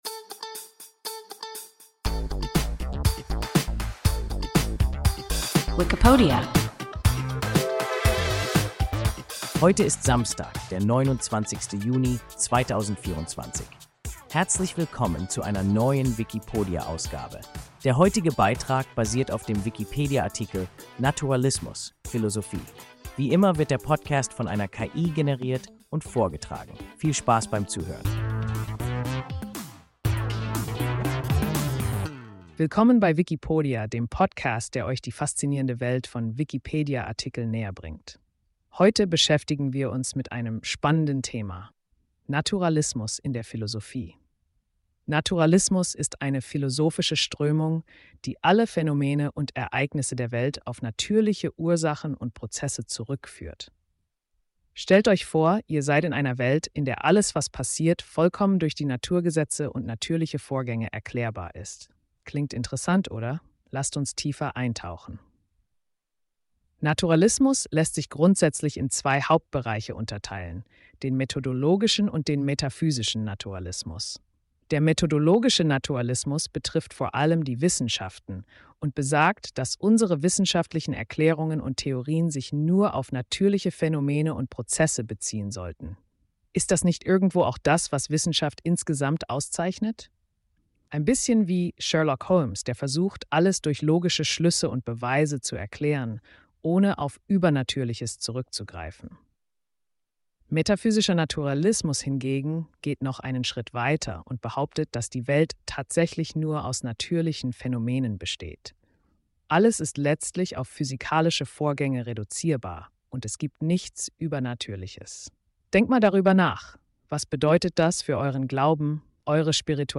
Naturalismus (Philosophie) – WIKIPODIA – ein KI Podcast